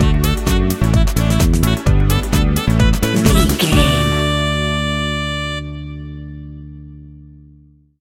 Aeolian/Minor
groovy
driving
energetic
bass guitar
drums
saxophone
electric piano
upbeat